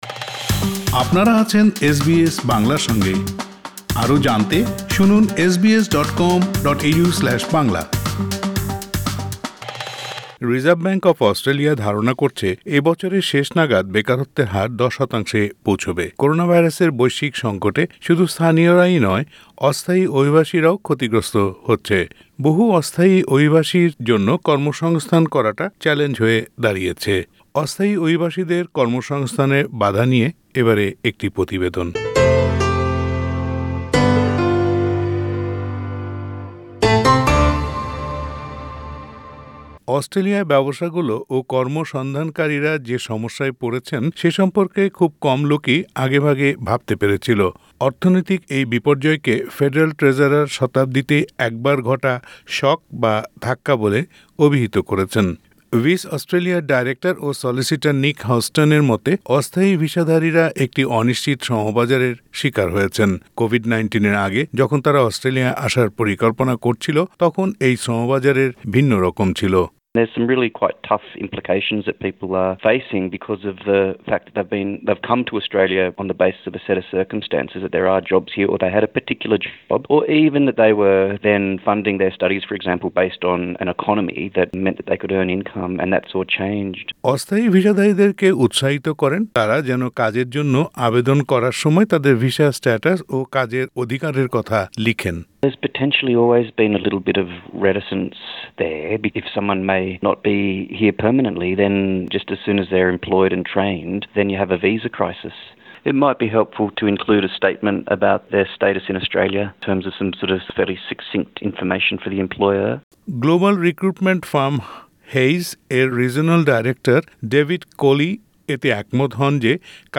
অস্থায়ী অভিবাসীদের কর্ম-সংস্থানে বাধা নিয়ে প্রতিবেদনটি শুনতে উপরের অডিও ক্লিপটির লিংকটিতে ক্লিক করুন।